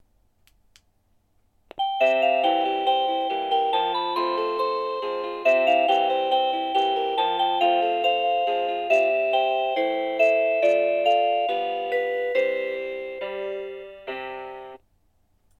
• 36 vyzváněcí tónů k výběru, ukázky zvonění: